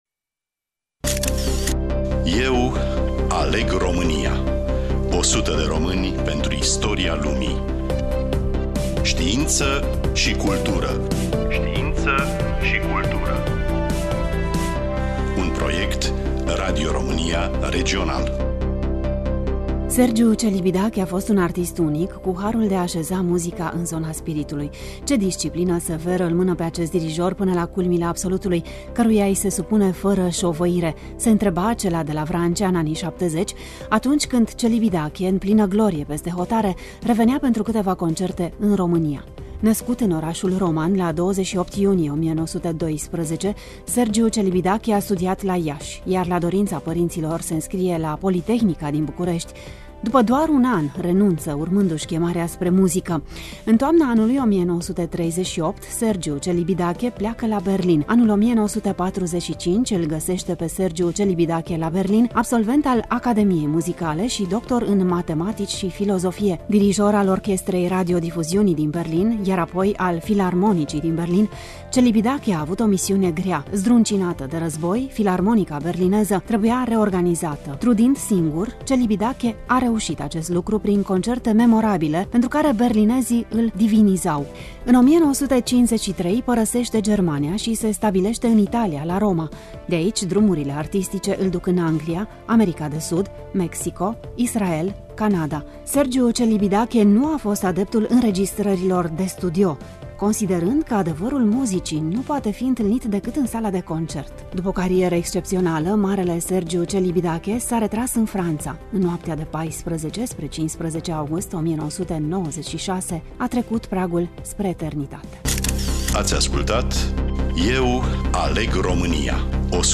Studioul: Radio România Tg.Mureş